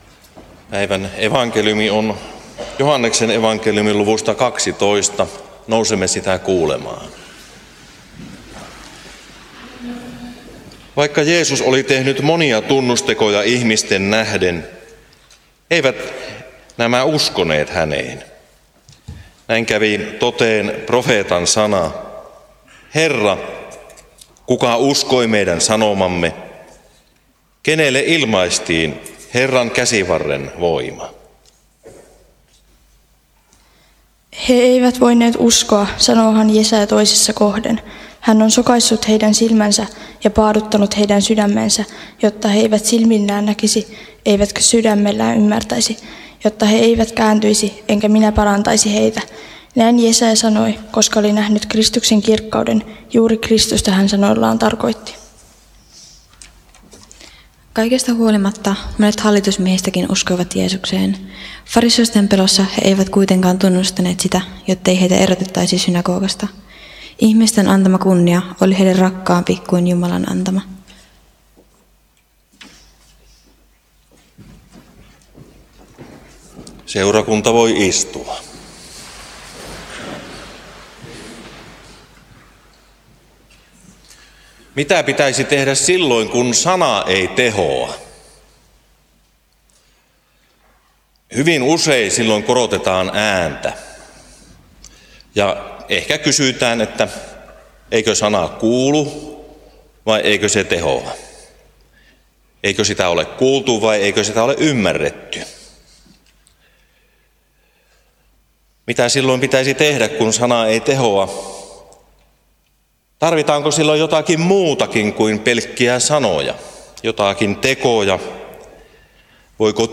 Alajärvellä 3. paastonajan sunnuntaina Tekstinä Joh. 12:37–43